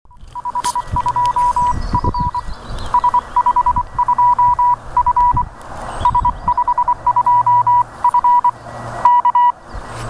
Выходил в поля- леса, возможность была, поработать QRP/p. В обед открылось прохождение,